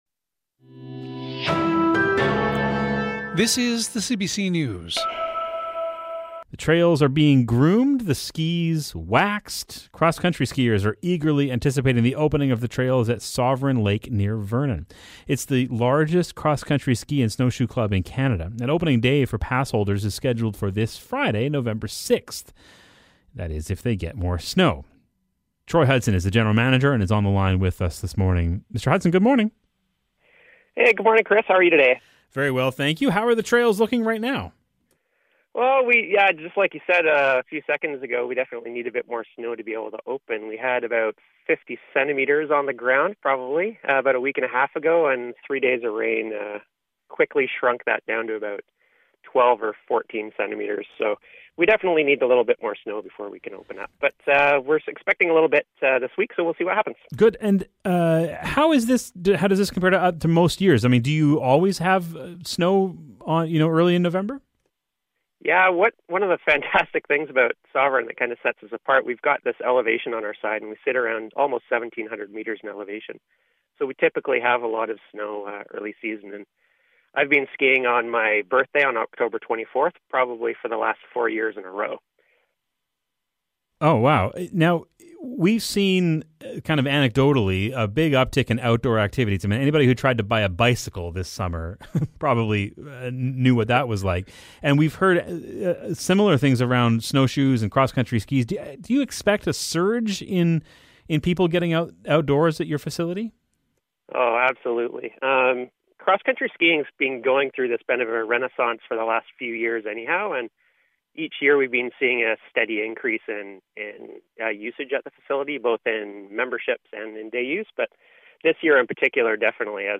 CBC interview